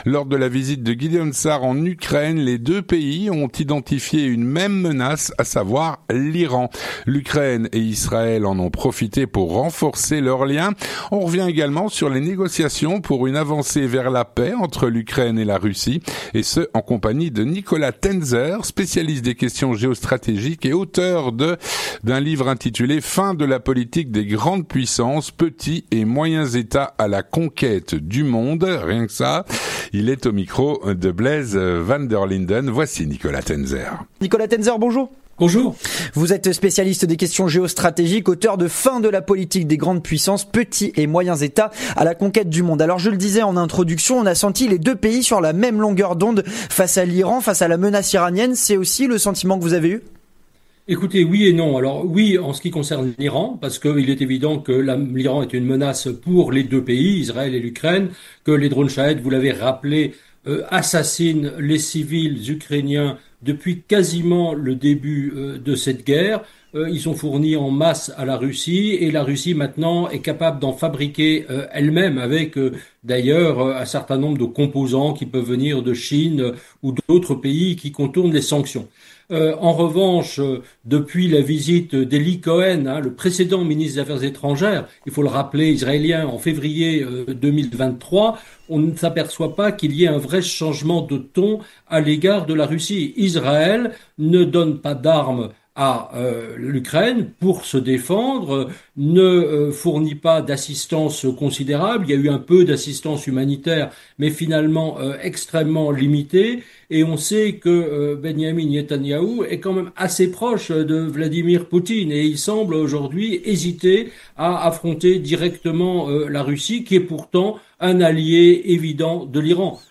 L'entretien du 18H - Lors de la visite de Gideon Saar en Ukraine, Israël et l'Ukraine ont identifié une même menace : l’Iran !